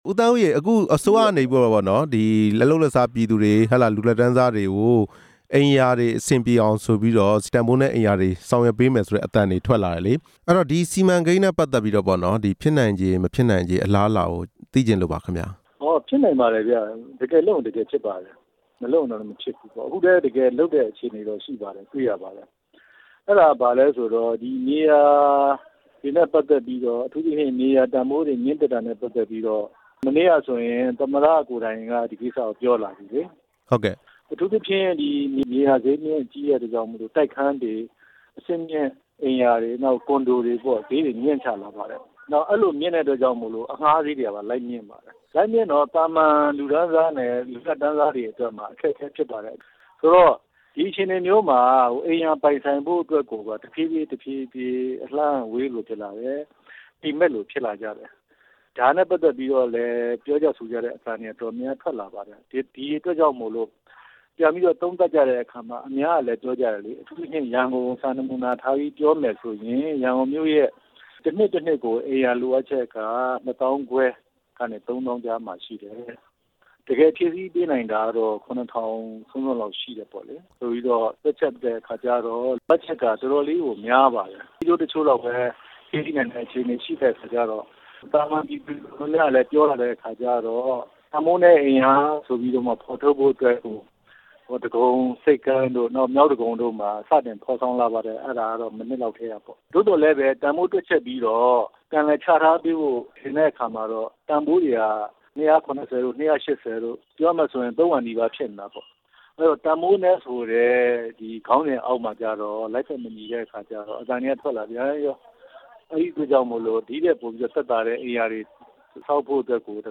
တန်ဖိုးနည်းအိမ်ရာ ဖော်ဆောင်ရေး ဆက်သွယ်မေးမြန်းချက်